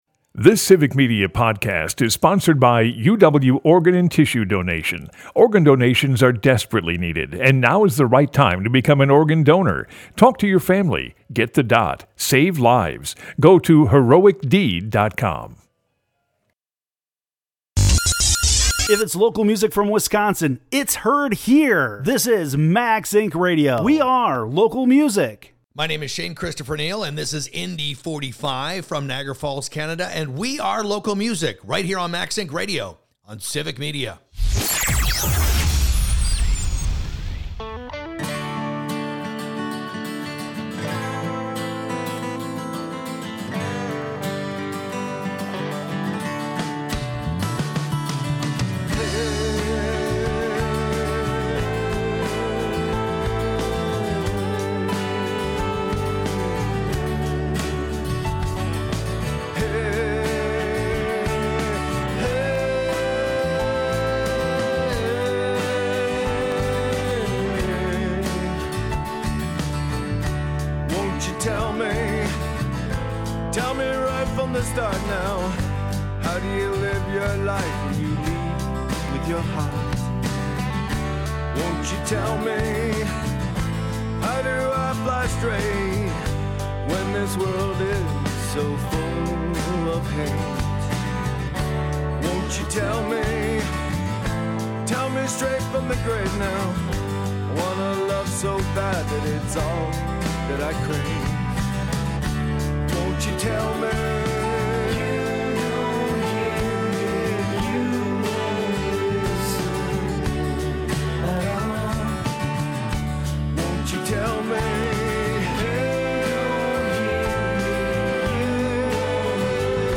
Max Ink Radio is Wisconsin’s local music radio show originating in Madison and featuring music, interviews, performances, and premieres of Wisconsin artists.